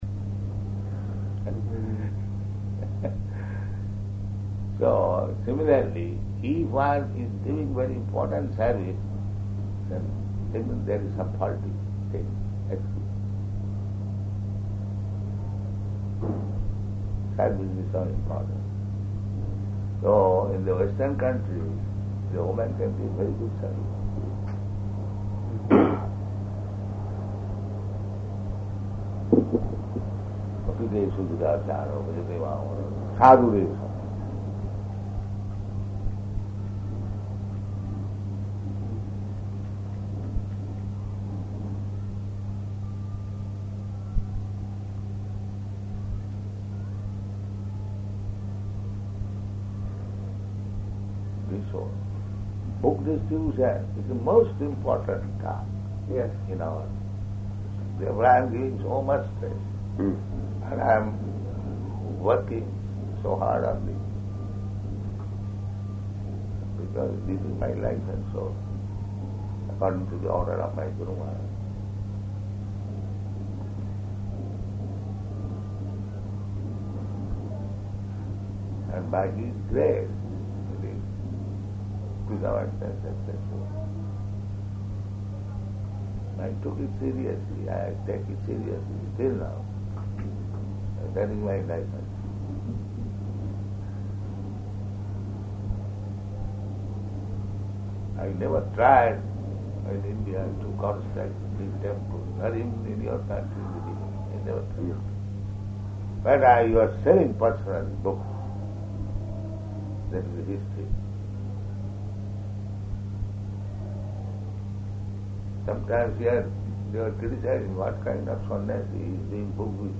Room Conversation
Type: Conversation